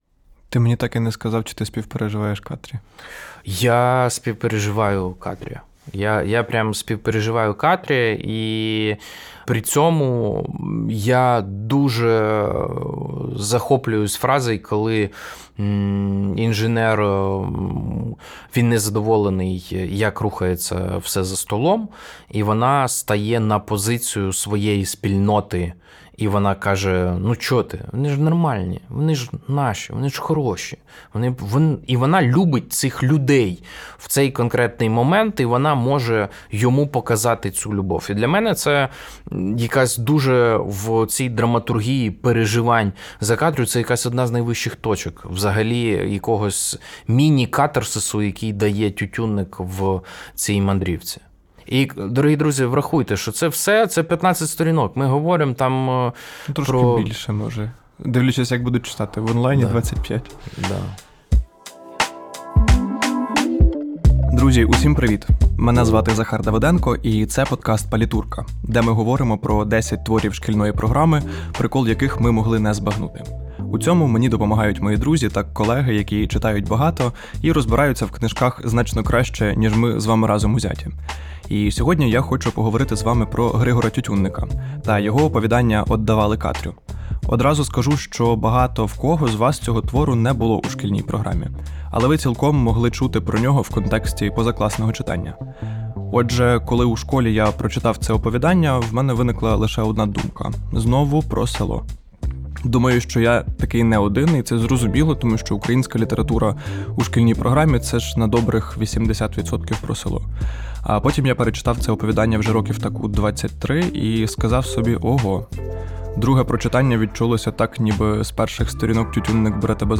8: Німий крик посеред свята | Розмова